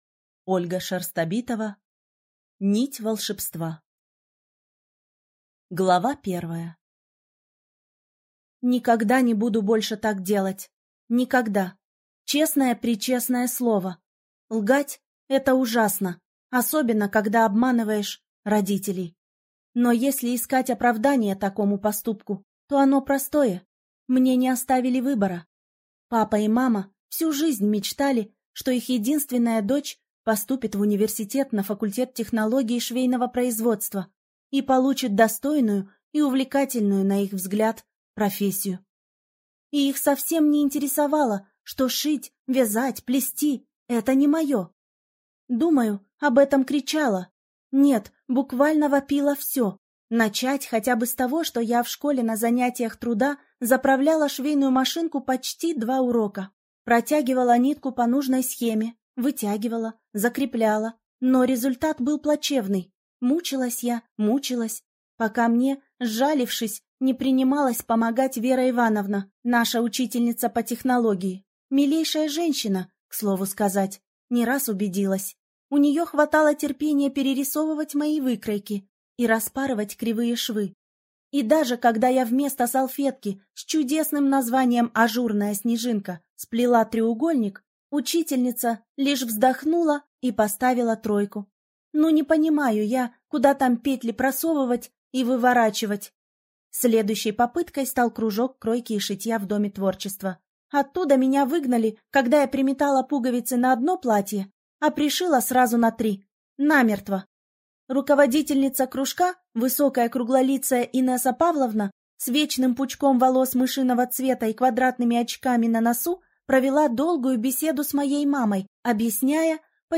Аудиокнига Нить волшебства - купить, скачать и слушать онлайн | КнигоПоиск